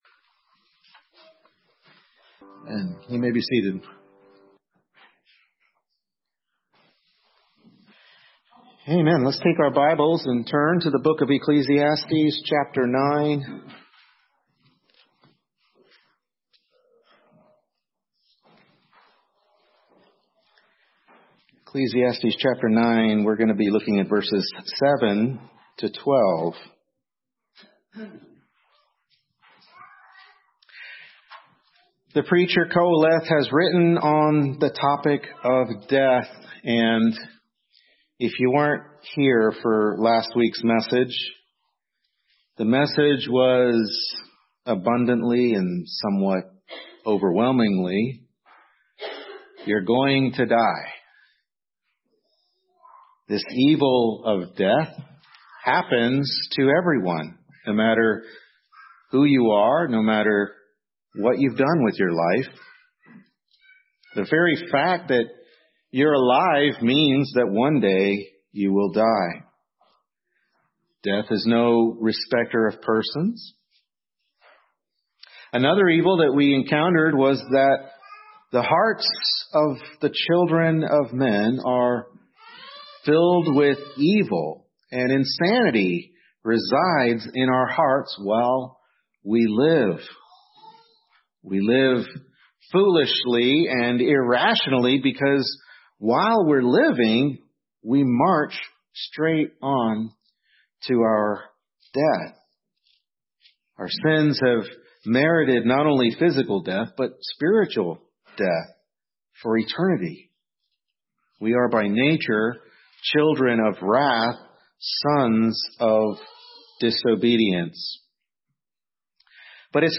Ecclesiastes 9:7-12 Service Type: Morning Worship Service Ecclesiastes 9:7-12 Life is Short